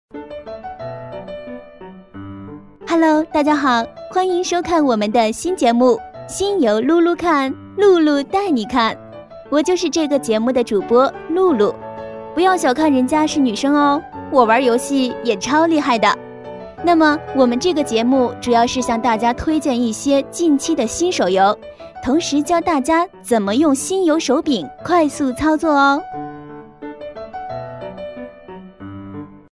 女24-游戏CG【手游解说】
女24-游戏CG【手游解说】.mp3